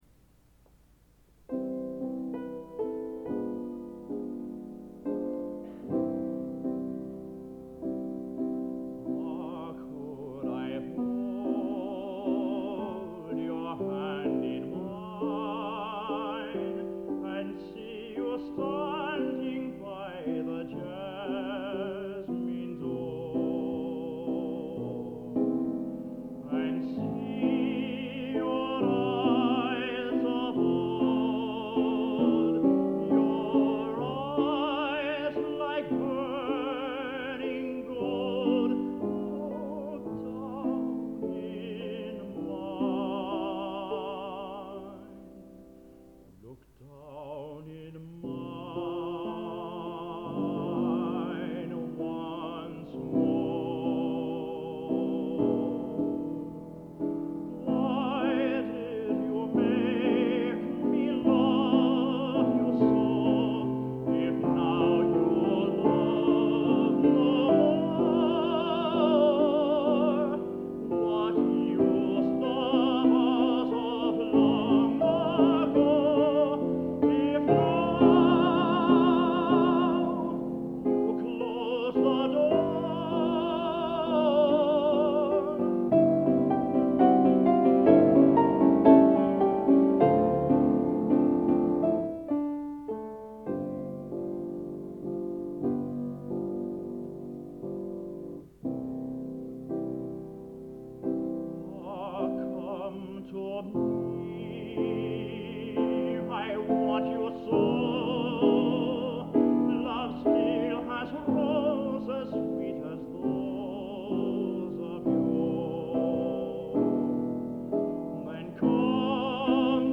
Collection: Plymouth, England
Location: Plymouth, England